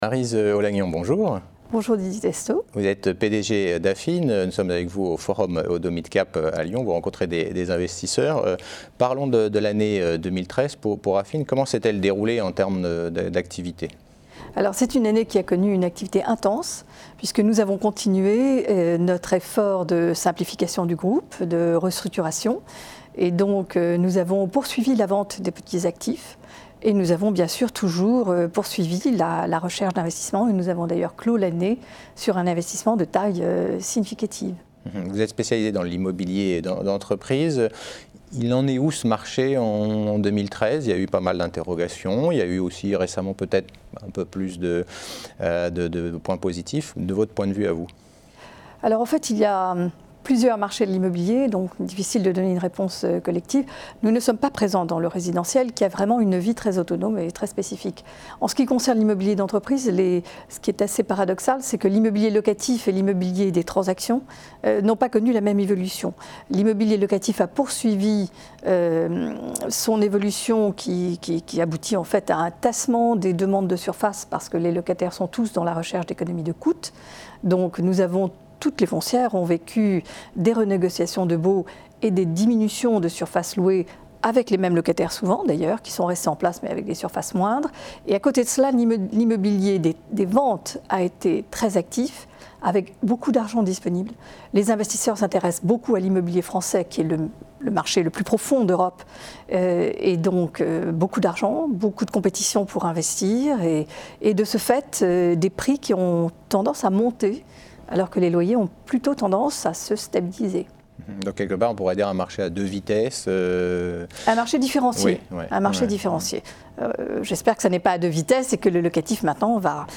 Oddo Midcap Forum 2014 : Stratégie et perspectives d'Affine